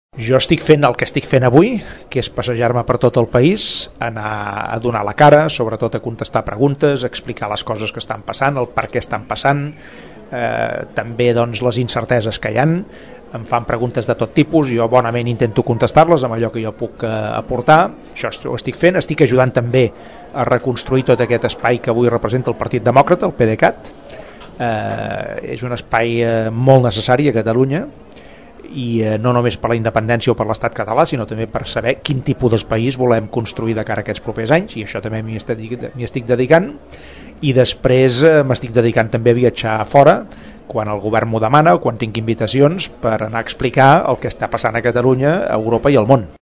En declaracions a Ràdio Tordera, Artur Mas explica quina feina fa a dia d’avui.